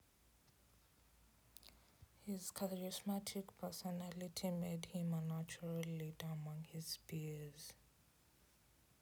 speech-emotion-recognition
sad.wav